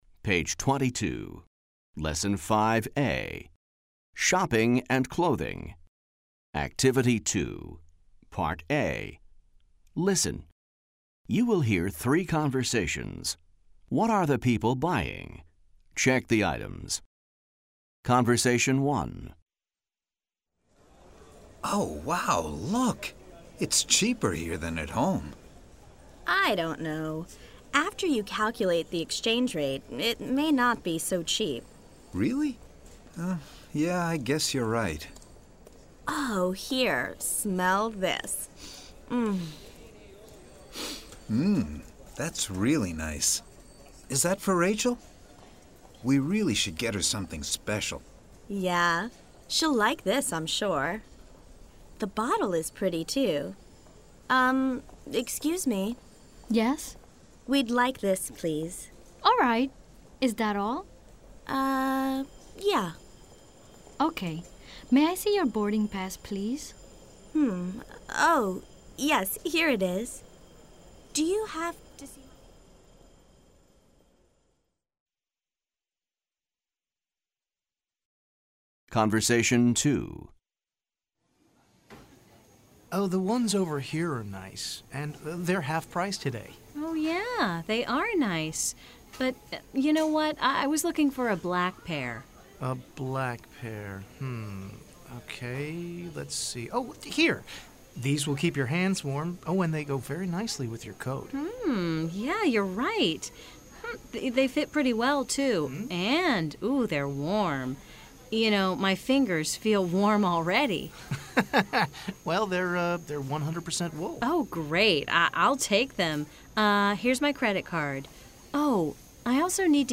American English
New recordings offer authentic listening experiences in a variety of genres, including conversations, interviews, and radio and TV shows.